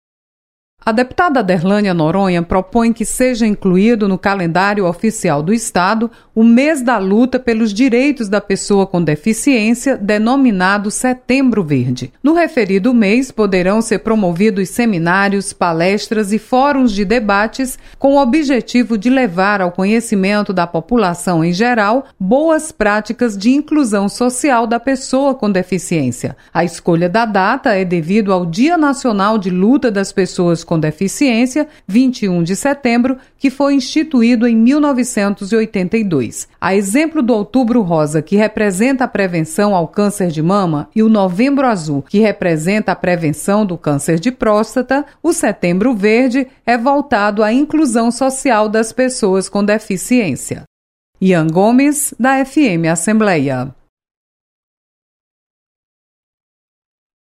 Deputada propõe mais visibilidade para as pessoas com deficiência. Repórter